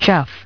Transcription and pronunciation of the word "chough" in British and American variants.